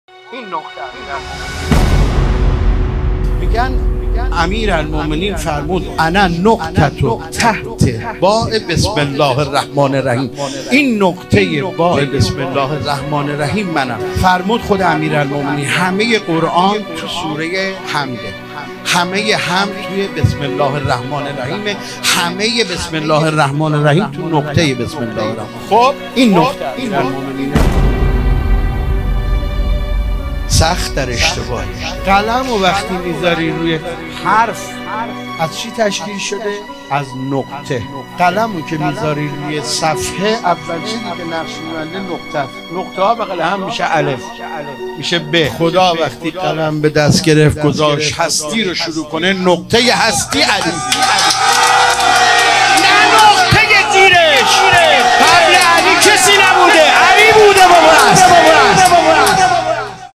هیئت جنت العباس کاشان